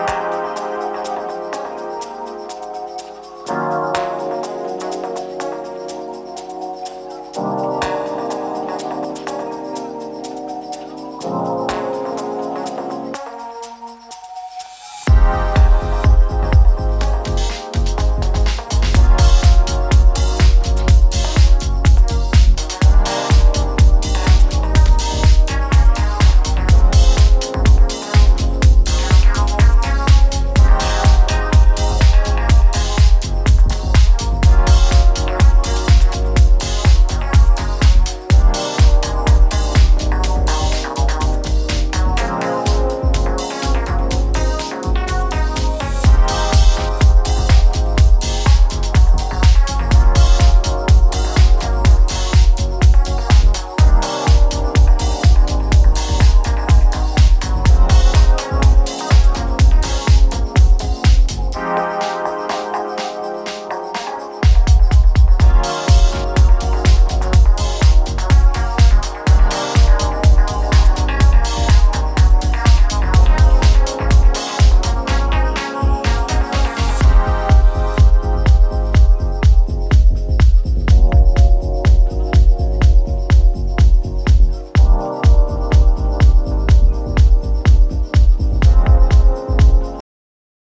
モダンなセンスのディープ・ハウス群を展開。